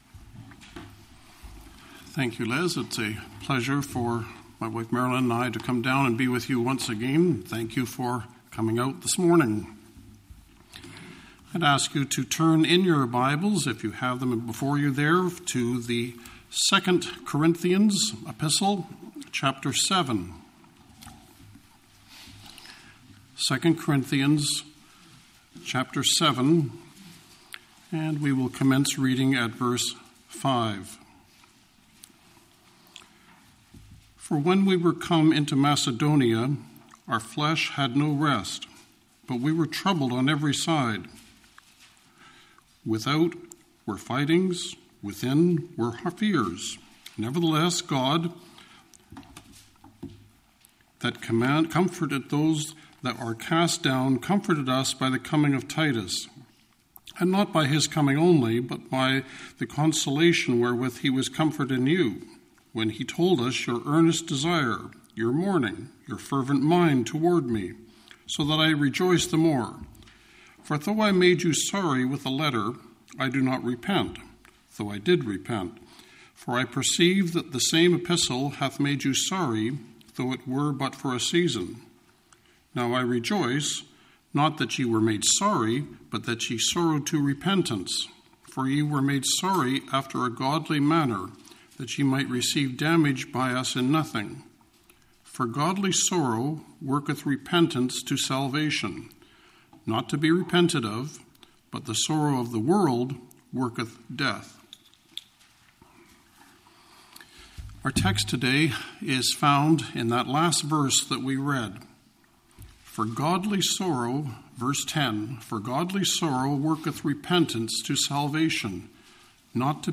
Passage: 2 Cor 7:5-10 Service Type: Family Bible Hour « 3 Things Paul Thanks God For In The Corinthian Church What Is Your Response To The Cross of Jesus Christ?